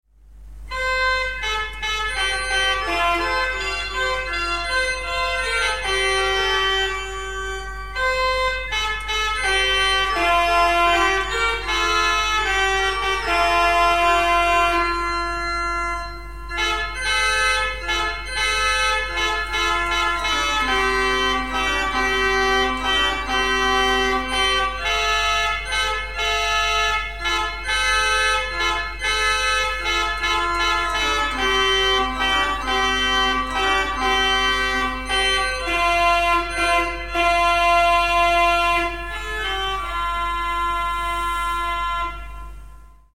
Chanson
Chant populaire du Nivernais